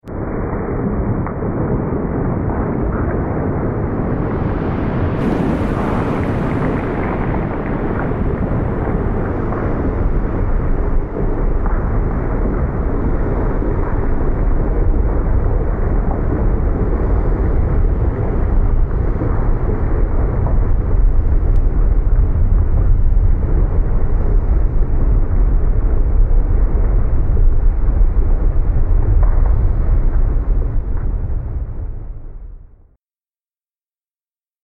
دانلود آهنگ آتشفشان 4 از افکت صوتی طبیعت و محیط
جلوه های صوتی
دانلود صدای آتشفشان 4 از ساعد نیوز با لینک مستقیم و کیفیت بالا